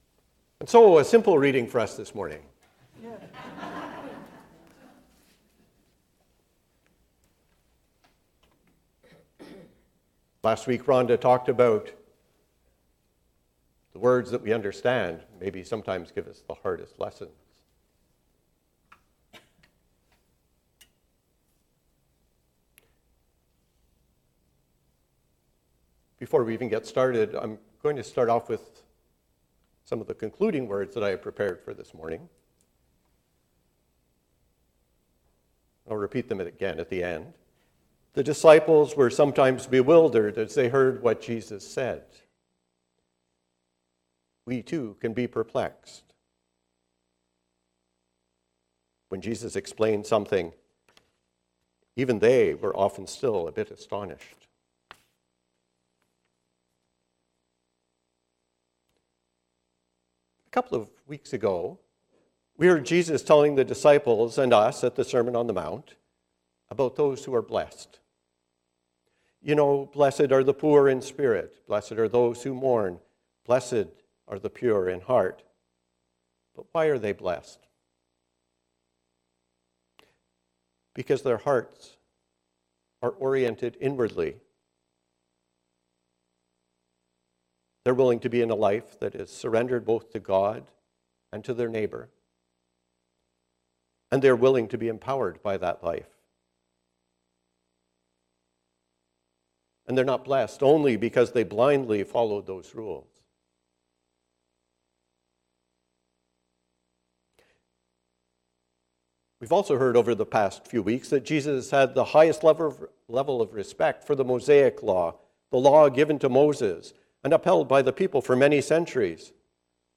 A sermon on Matthew 5:21-37